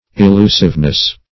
-- E*lu"sive*ly, adv. -- E*lu"sive*ness, n.